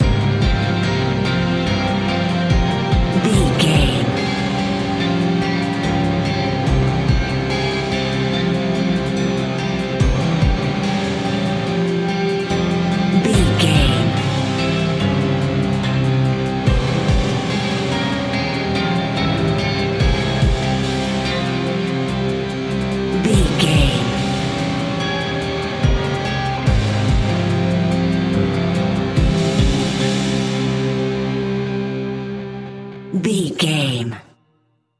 Fast paced
In-crescendo
Ionian/Major
industrial
dark ambient
EBM
synths
Krautrock